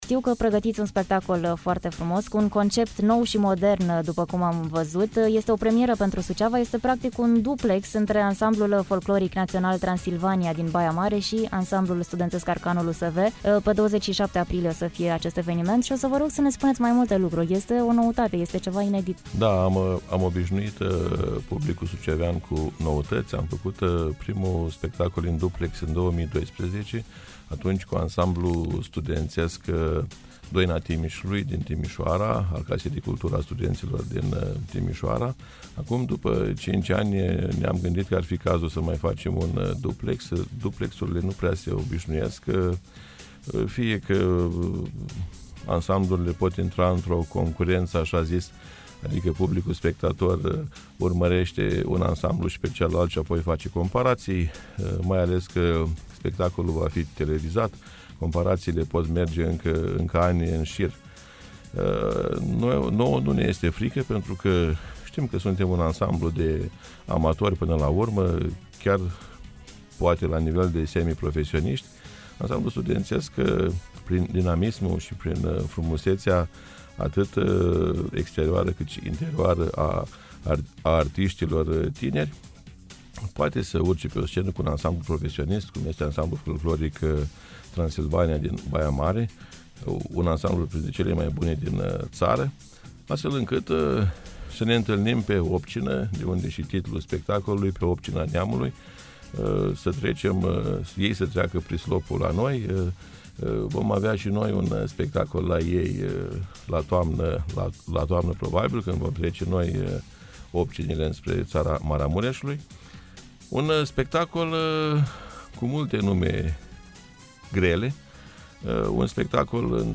Evenimentul este cu siguranţă o premieră absolută pentru Suceava, fiind vorba despre un duplex între Ansamblul Folcloric Național „Transilvania” din Baia Mare și Ansamblul Studențesc ARCANUL USV al Universității „Ștefan cel Mare” din Suceava. Pornind de la distribuţie şi continuând cu nume cunoscute din domeniu, întregul spectacol va fi o adevărată desfăşurare de forţe despre care puteţi afla mai multe ascultând interviul de mai jos.